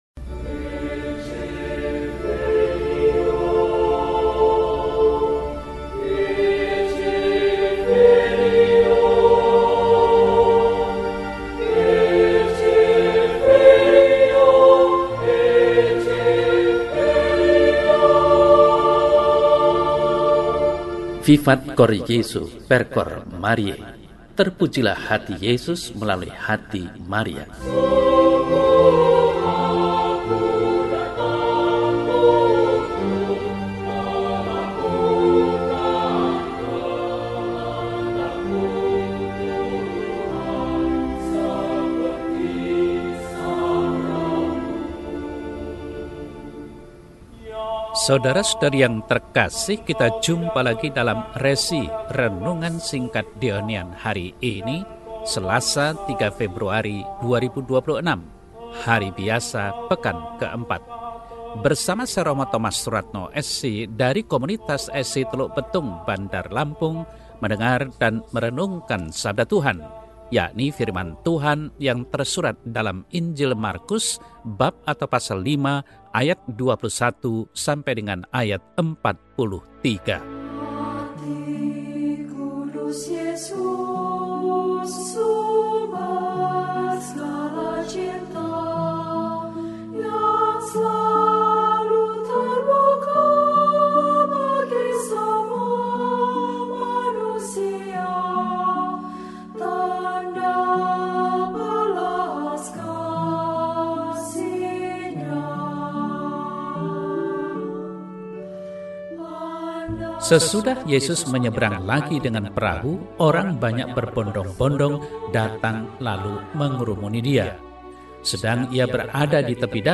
Selasa, 03 Februari 2026 – Hari Biasa Pekan IV – RESI (Renungan Singkat) DEHONIAN